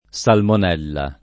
salmonella [ S almon $ lla ]